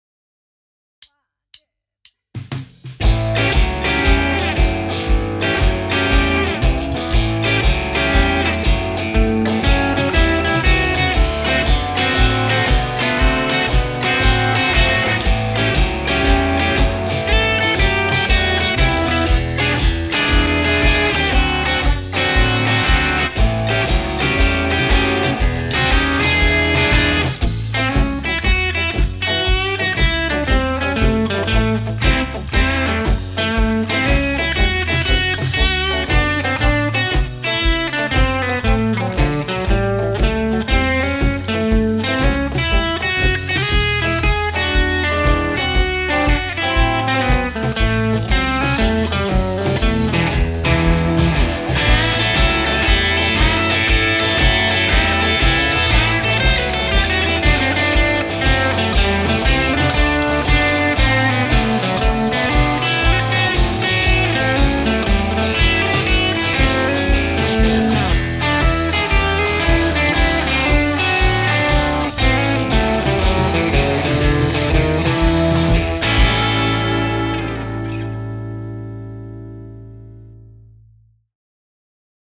Shuffle Blues